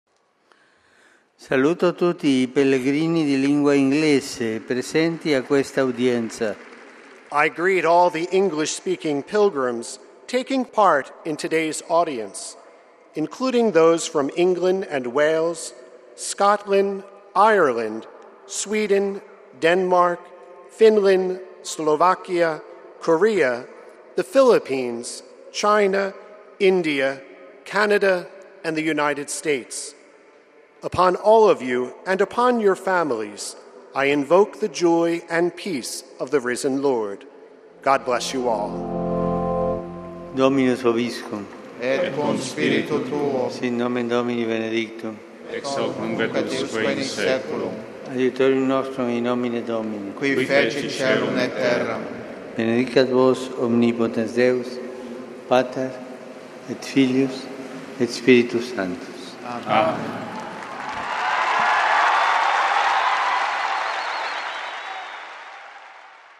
The general audience of May 7th in Rome’s St. Peter’s Square began with aides reading a passage from the Book of Psalms.
Pope Francis then greeted the English-speaking pilgrims in Italian.